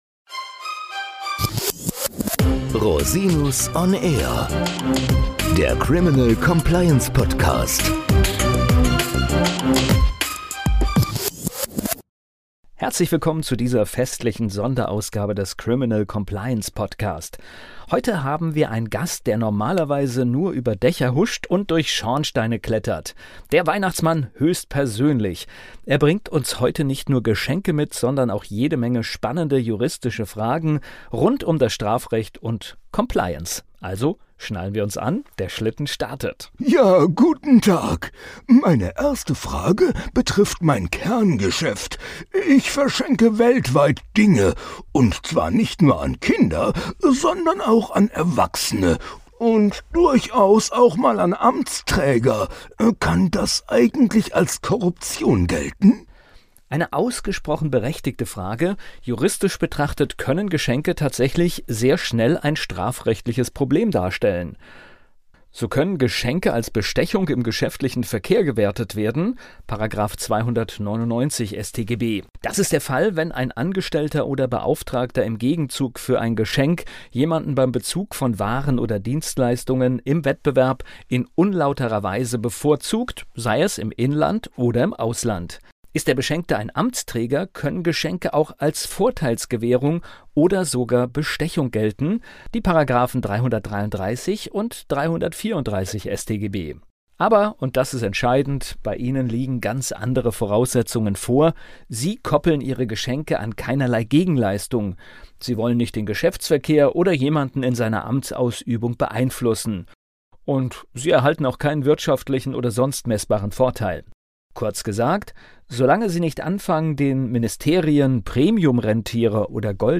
Weihnachtsfolge: Interview mit dem Weihnachtsmann